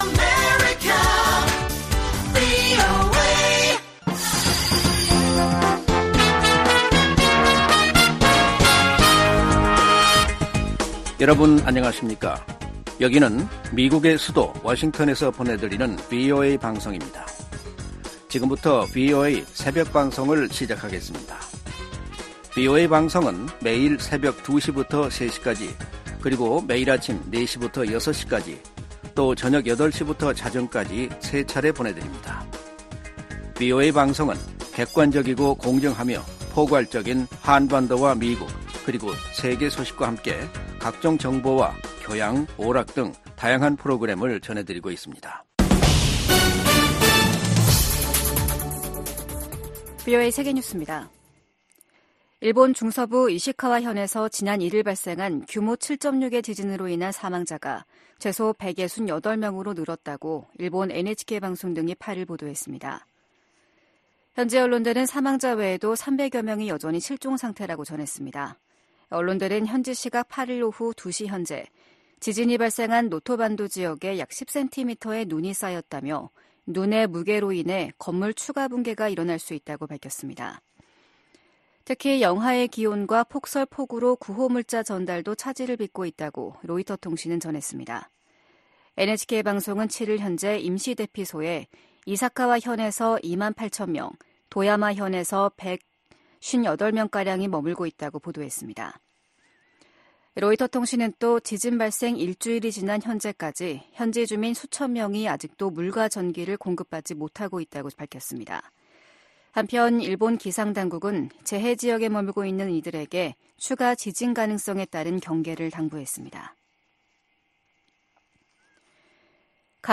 VOA 한국어 '출발 뉴스 쇼', 2024년 1월 9일 방송입니다. 북한 군이 한국의 서북도서 인근에서 포 사격을 실시하자 한국 군도 해당 구역 군사훈련을 재개하기로 했습니다. 미 국무부는 북한의 서해 해상 사격에 도발 자제와 외교적 해결을 촉구했습니다. 미국 정부는 팔레스타인 무장정파 하마스가 북한 로켓 부품을 이용해 신무기를 만들고 있는 것과 관련해, 북한은 오래 전부터 중동 지역에 무기를 판매해 오고 있다고 밝혔습니다.